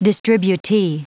Prononciation du mot distributee en anglais (fichier audio)
Prononciation du mot : distributee